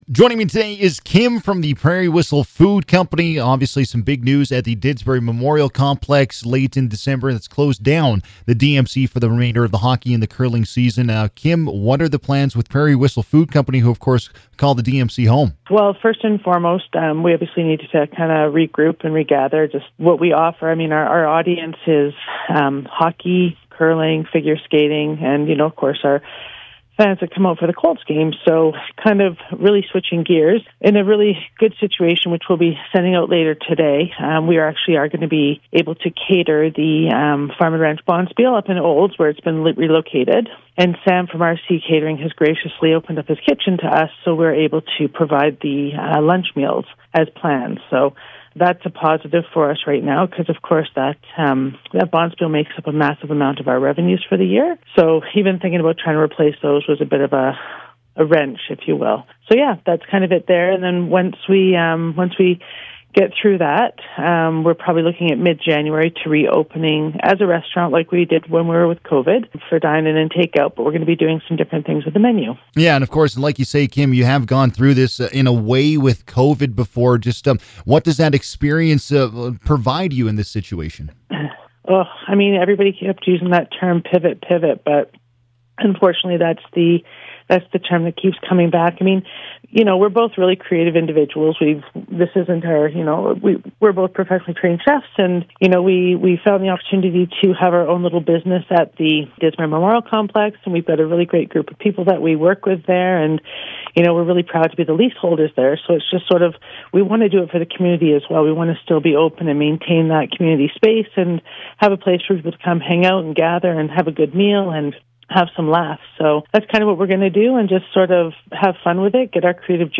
96.5 CKFM Community Hotline conversation